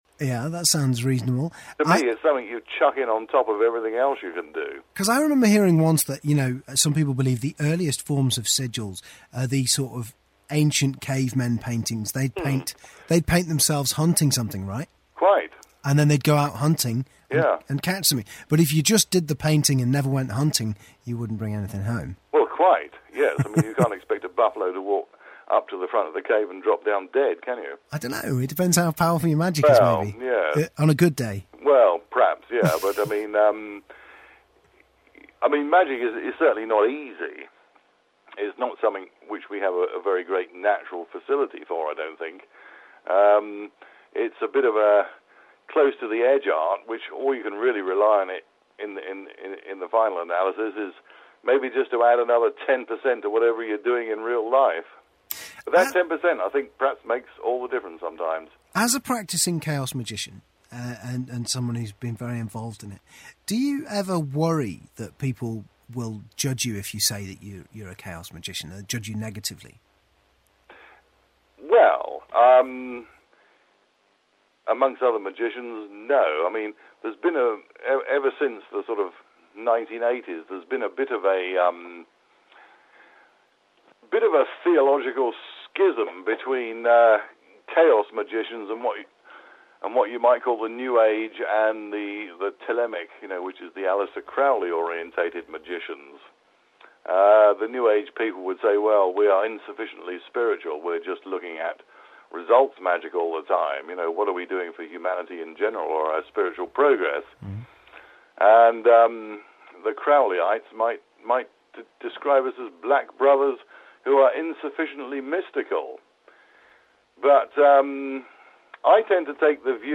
Final bit of the interview